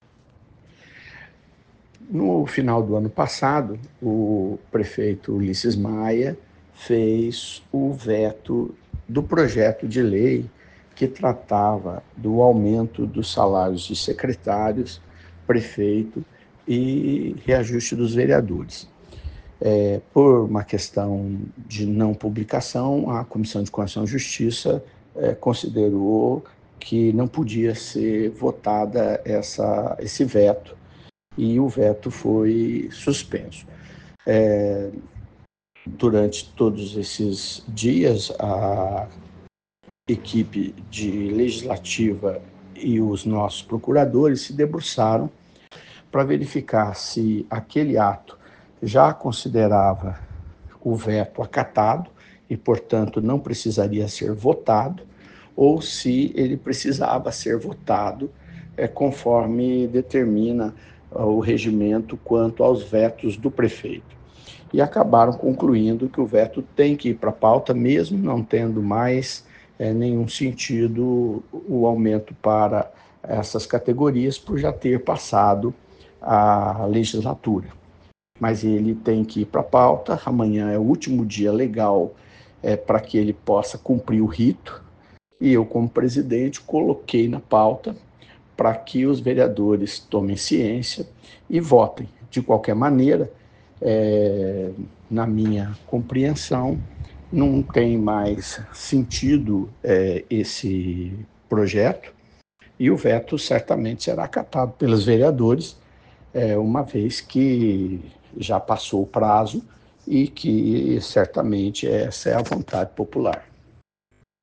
PRESIDENTE-INTERINO-SIDNEI-TELLES-EXPLICA-PORQUE-VETO-FOI-PAUTADO-_1.mp3